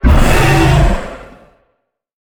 Sfx_creature_hiddencroc_flinch_01.ogg